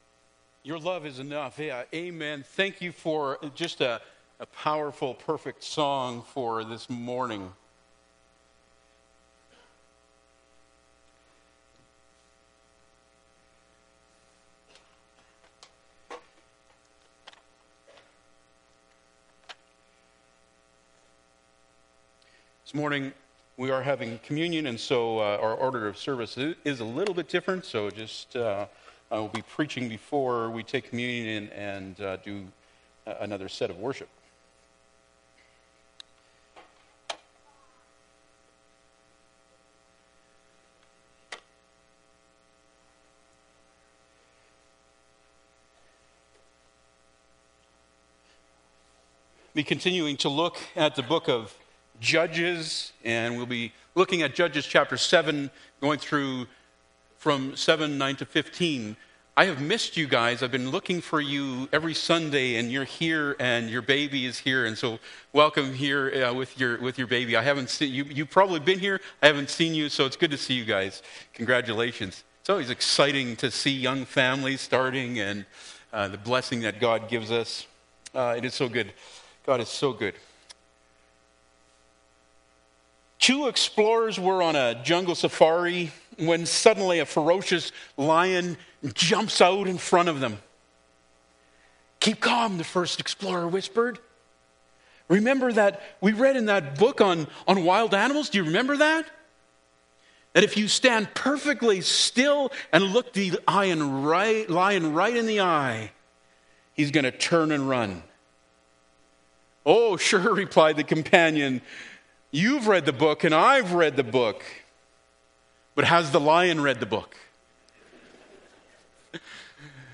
Judges 7:9-15 Service Type: Sunday Morning Bible Text